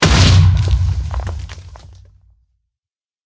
explode2.ogg